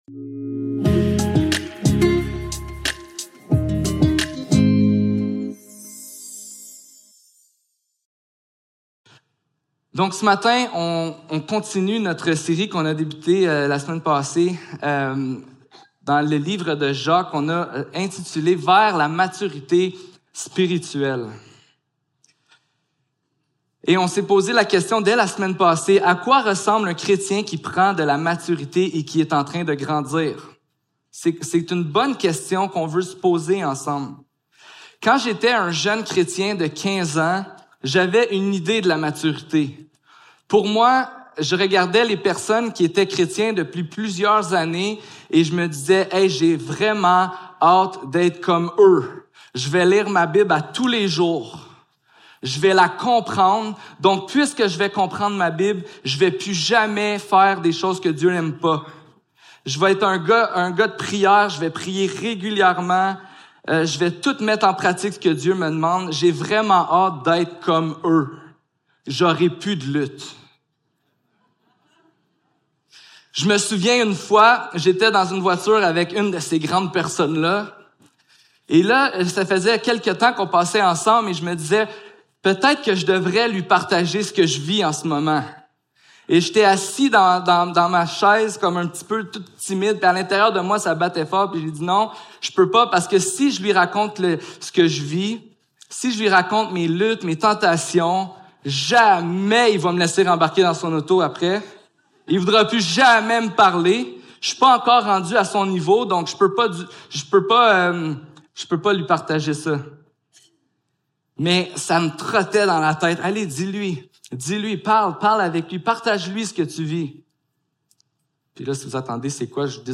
Service Type: Célébration dimanche matin